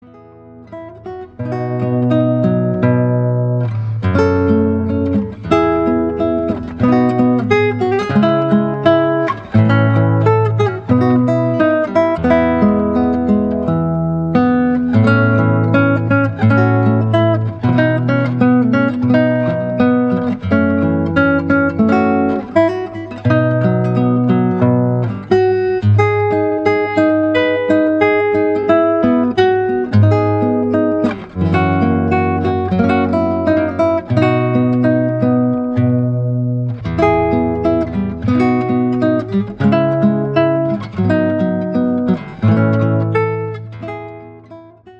Classical guitar arrangements